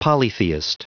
Prononciation du mot polytheist en anglais (fichier audio)
Prononciation du mot : polytheist